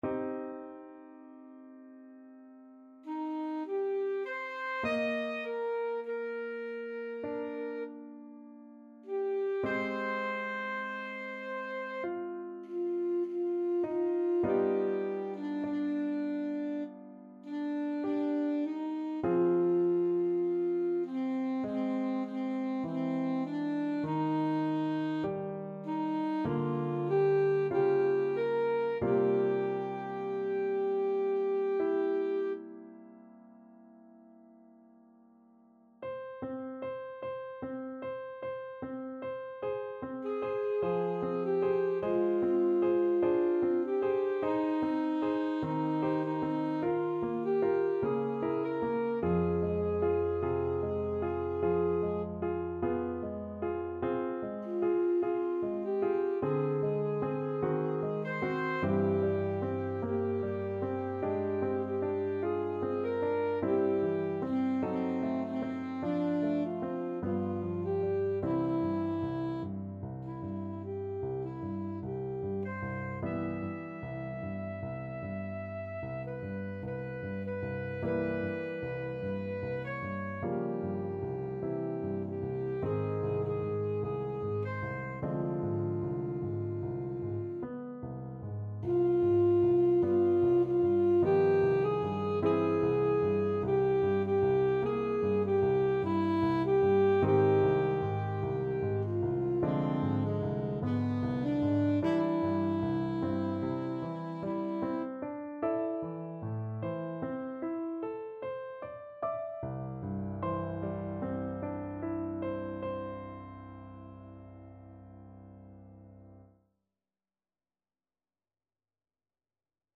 Alto Saxophone
4/4 (View more 4/4 Music)
C minor (Sounding Pitch) A minor (Alto Saxophone in Eb) (View more C minor Music for Saxophone )
~ = 100 Lento =50
rachmaninoff_op21_3_ASAX.mp3